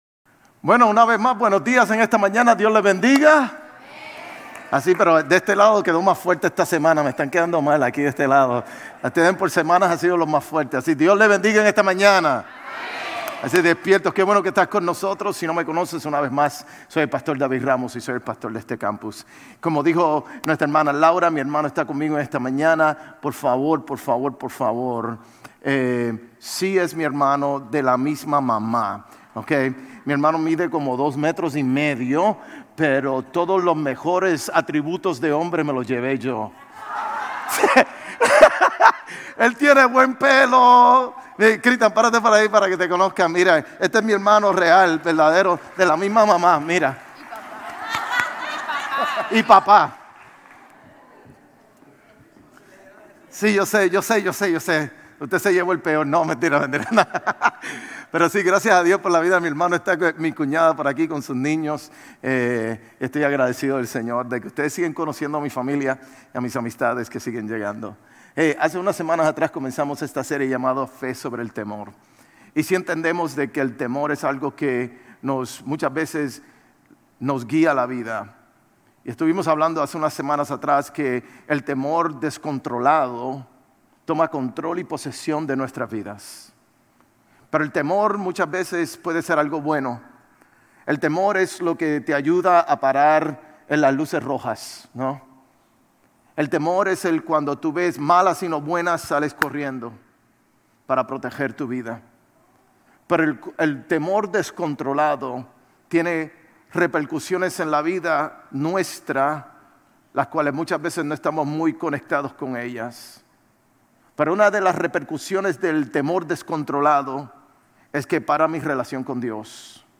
Sermones Grace Español 5_18 Grace Espanol Campus May 19 2025 | 00:42:57 1x 00:00 / 00:42:57 Subscribe Share RSS Feed Share Link Embed